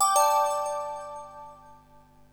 linkConfirmed.wav